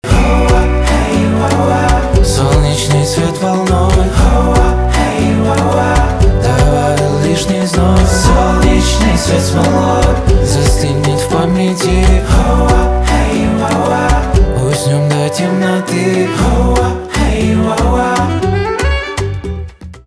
инди
барабаны , гитара
чувственные , саксофон